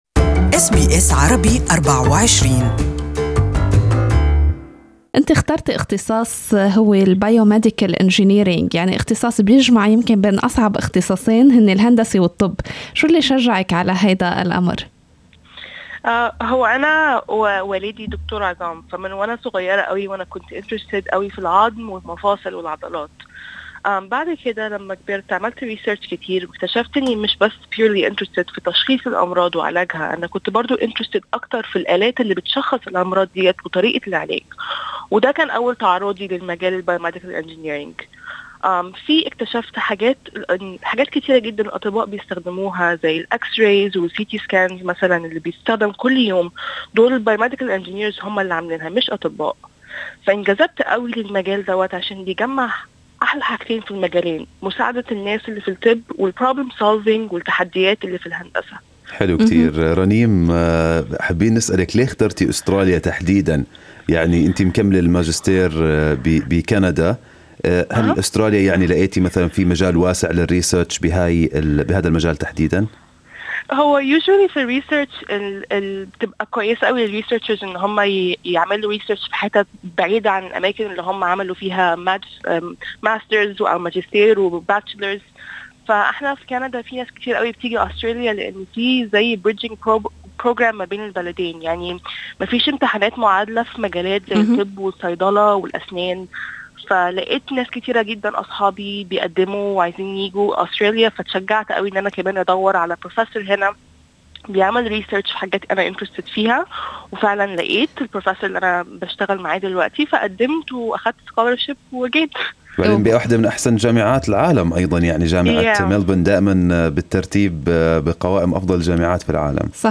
Good Morning Australia interviewed